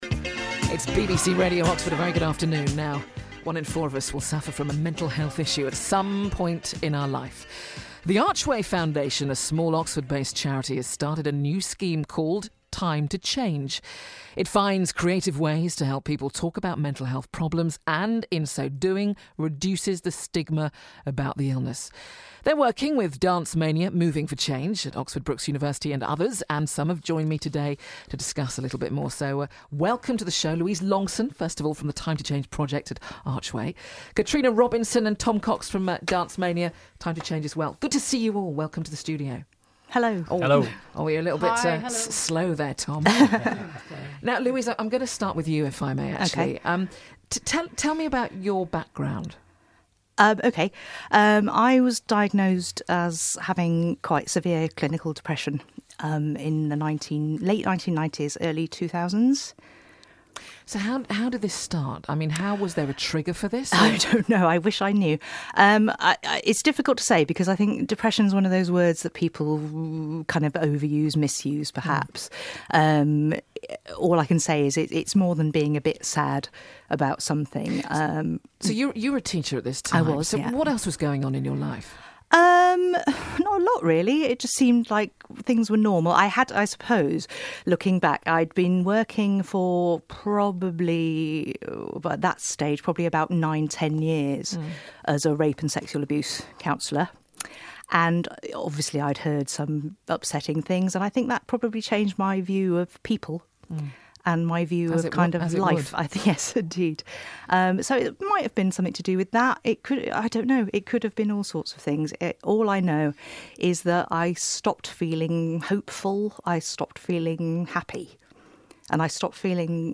talks to BBC Oxford about the stigma she suffered after being diagnosed with depression.